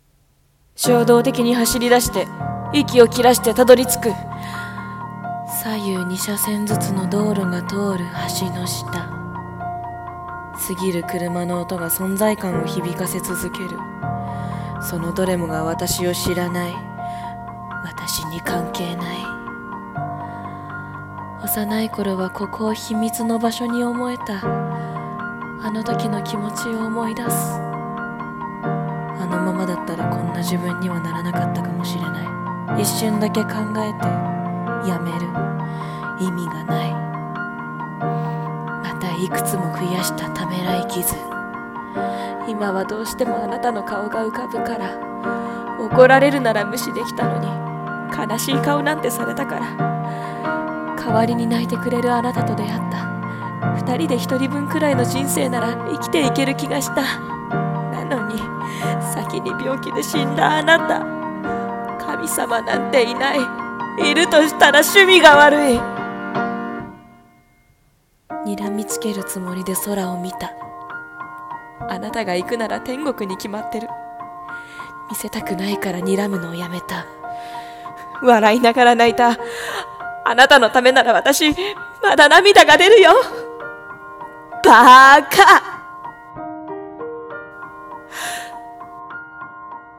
天国に、悪態。【一人声劇】